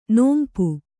♪ nōmpu